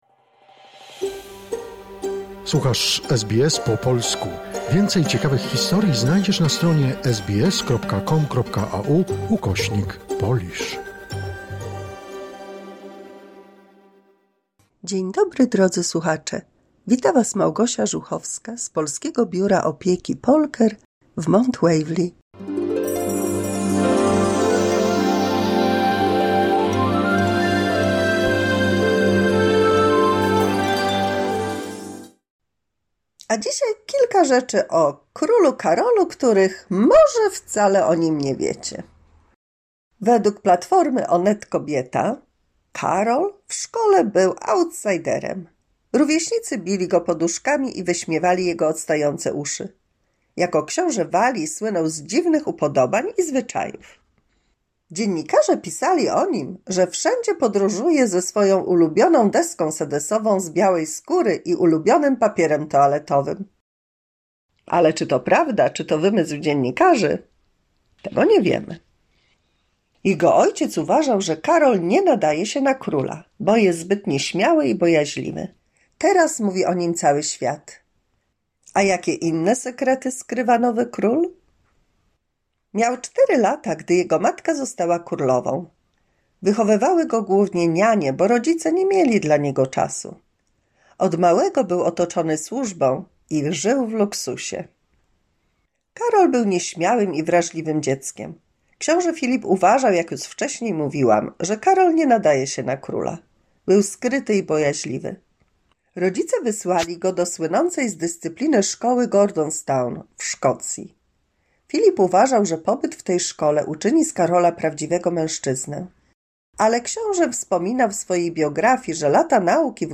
157 mini słuchowisko dla polskich seniorów